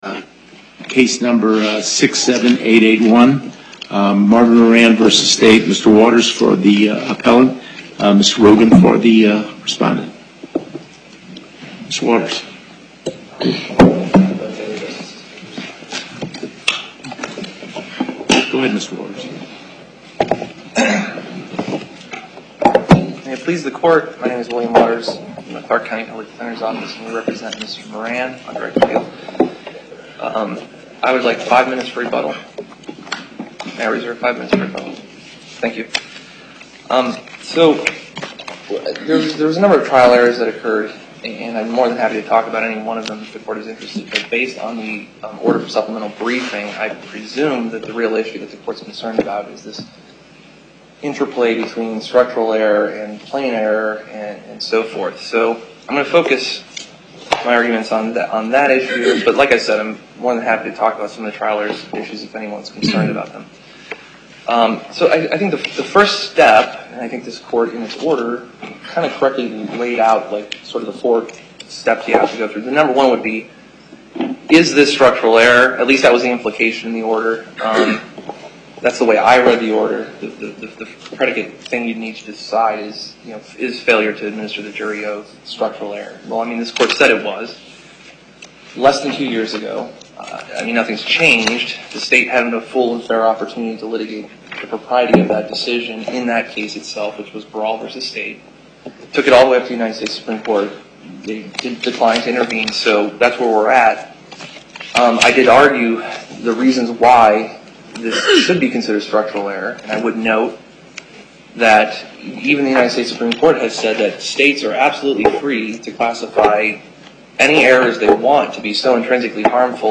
11:30 a.m. Location: Las Vegas Before the En Banc Court, Chief Justice Cherry presiding Appearances